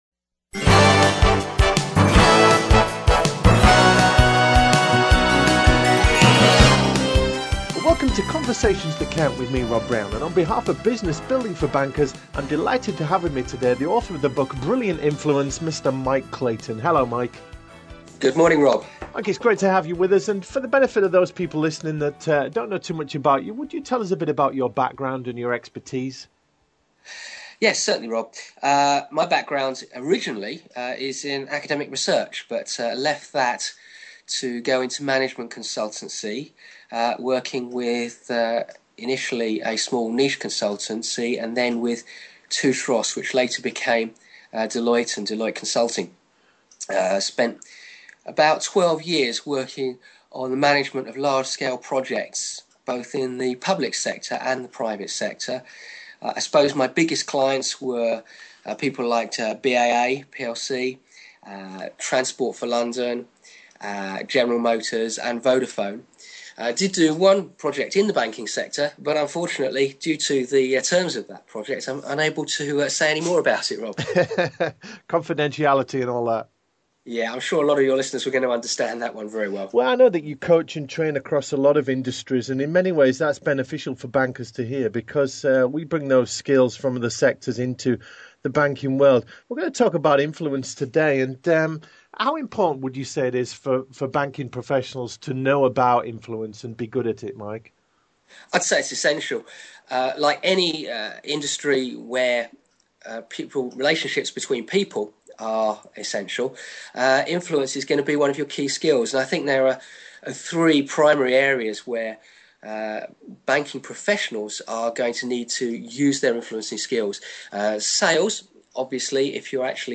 Brilliant Influence Interview